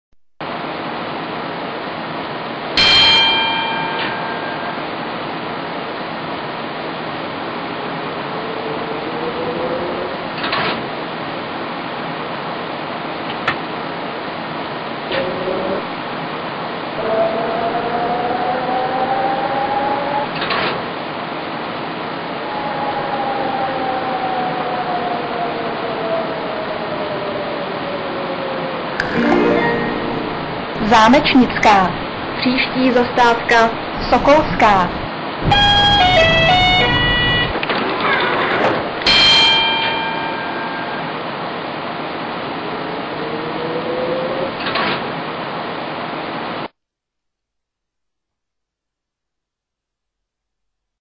Abych jen nekecal, tak jsem si trochu pohrál se zvukama zrychlovače. Odposlouchal jsem to teda v Brně, protože při mé návštěvě Olomouce jsem na zrychl nenarazil... Můžete si poslechnout, je to nahráno jen mikrofonem (Fraps mi nahrává zvuk v otřesné kvalitě).